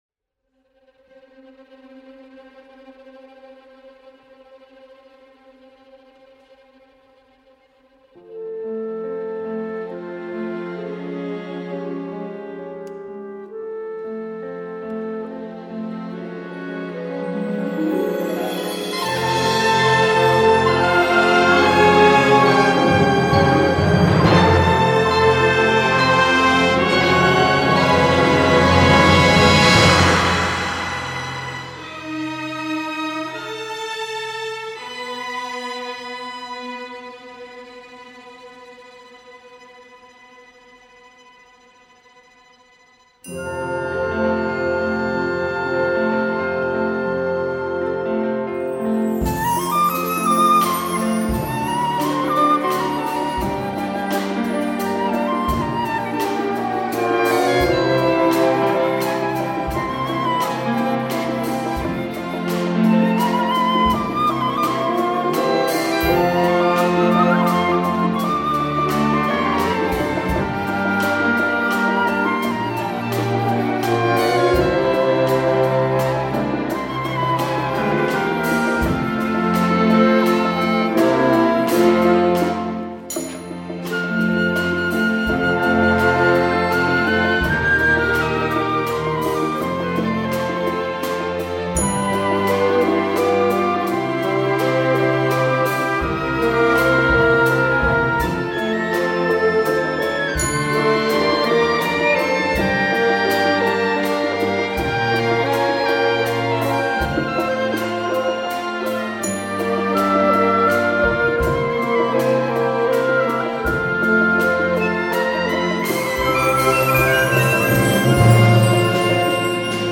симфоническая зарисовка посвящена одноименной вершине